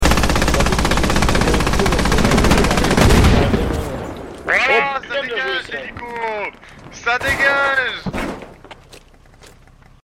" Helicopter Crash " dans sound effects free download